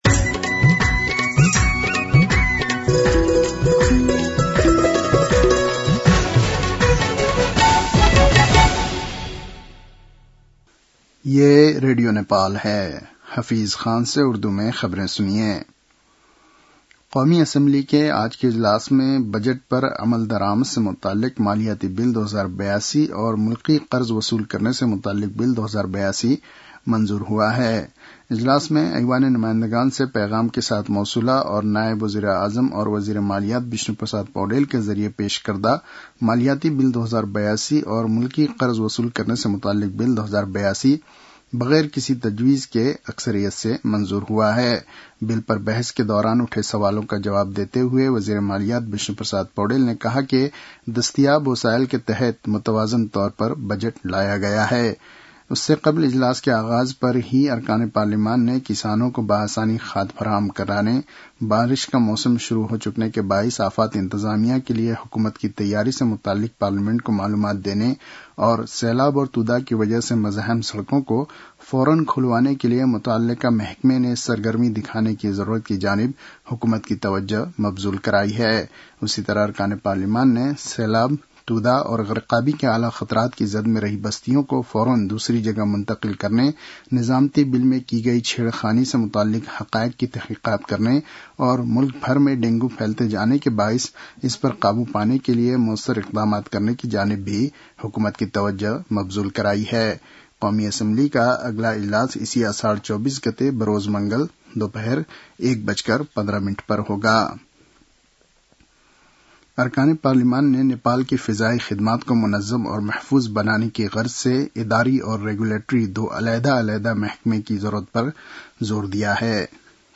उर्दु भाषामा समाचार : २० असार , २०८२